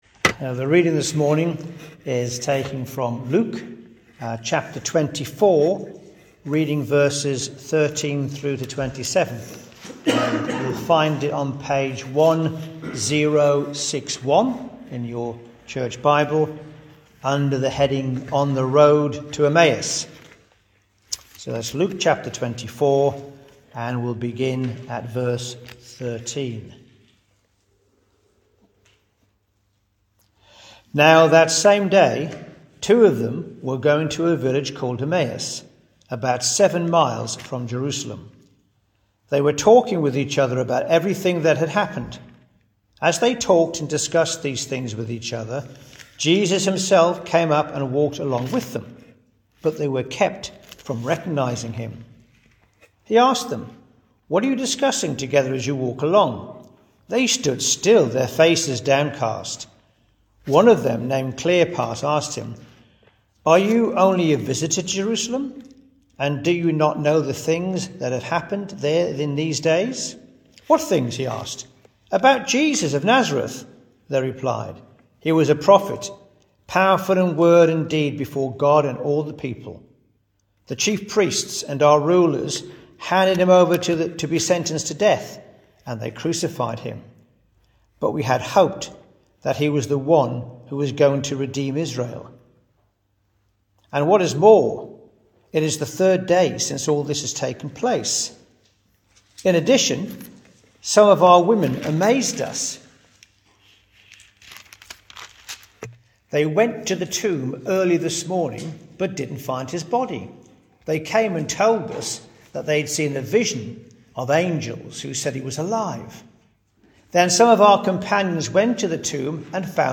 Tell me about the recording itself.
Luke 24:13-27 Service Type: Thursday 9.30am Topics